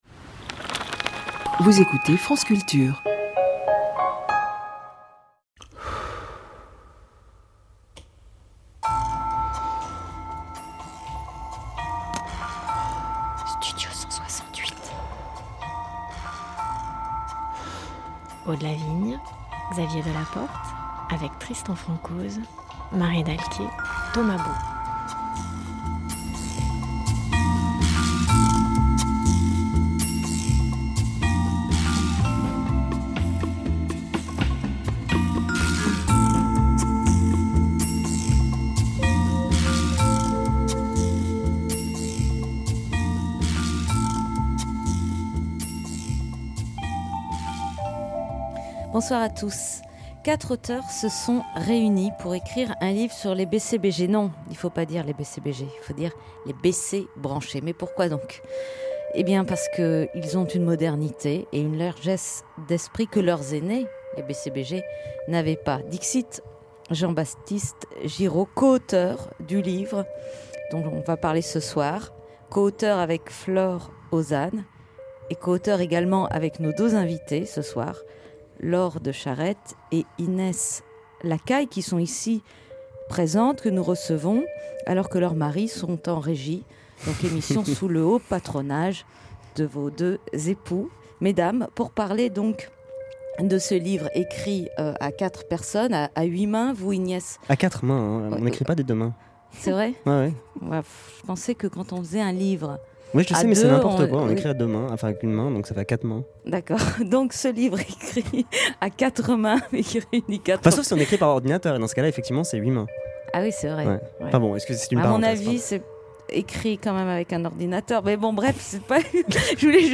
était invitée sur France Culture à l'émission Studio 168 animée par